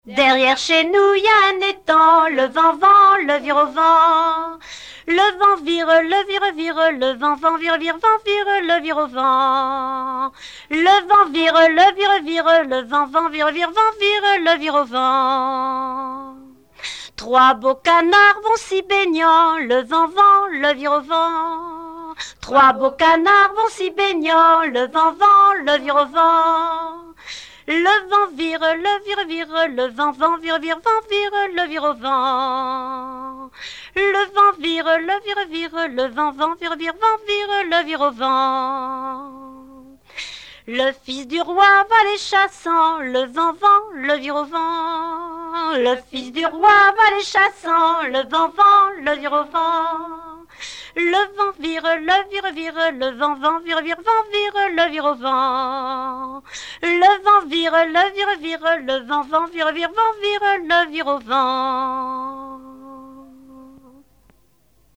gestuel : à marcher
Genre laisse
Chansons traditionnelles